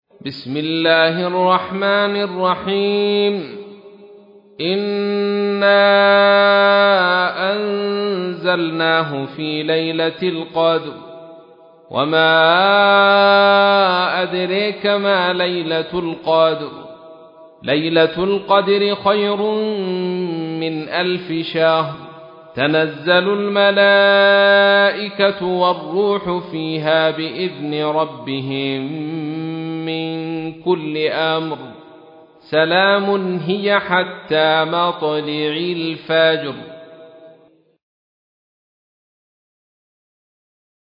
تحميل : 97. سورة القدر / القارئ عبد الرشيد صوفي / القرآن الكريم / موقع يا حسين